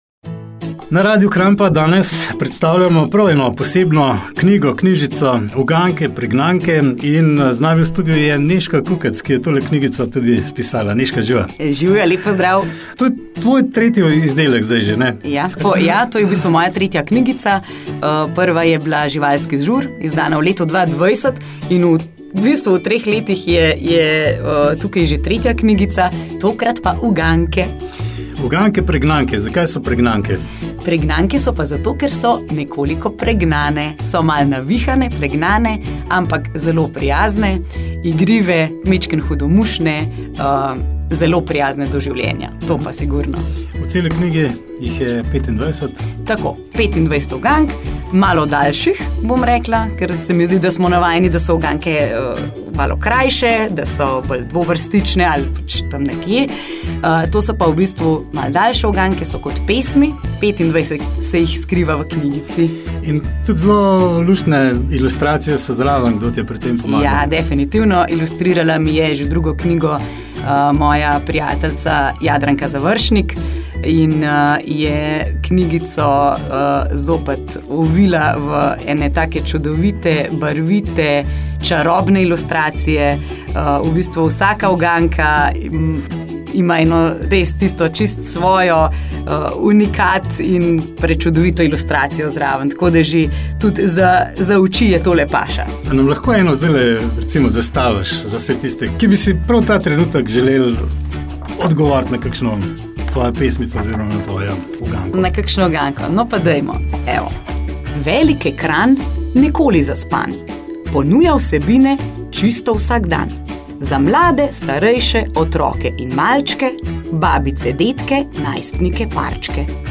Radio Kranj-pogovor
radio_kranj-pogovor.mp3